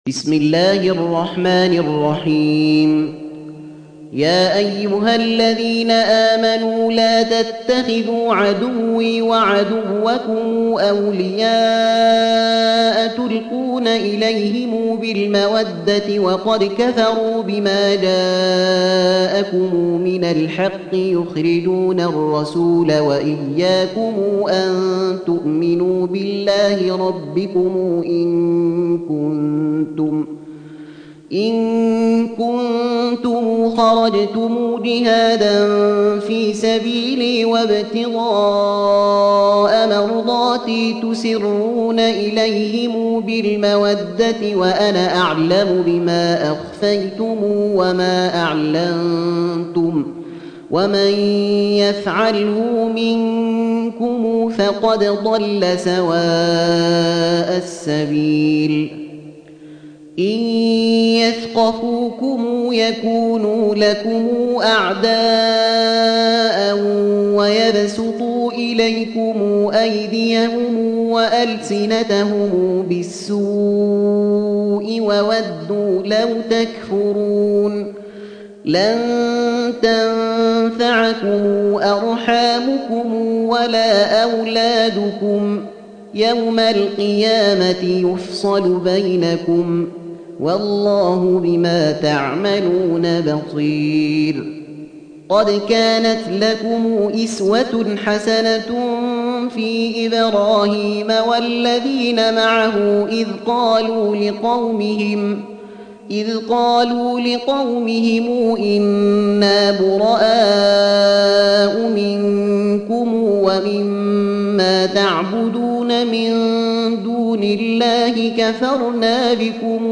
Surah Sequence تتابع السورة Download Surah حمّل السورة Reciting Murattalah Audio for 60. Surah Al-Mumtahinah سورة الممتحنة N.B *Surah Includes Al-Basmalah Reciters Sequents تتابع التلاوات Reciters Repeats تكرار التلاوات